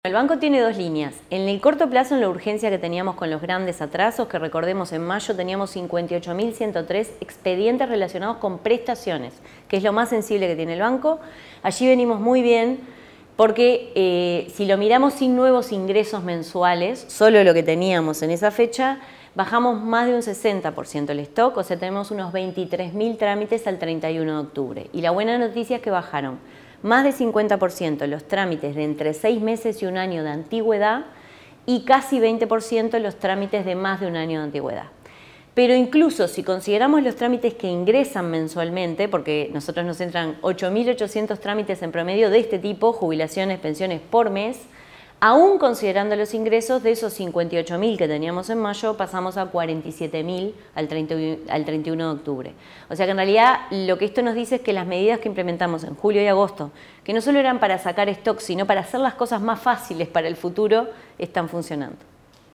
Declaraciones de la presidenta del BPS, Jimena Pardo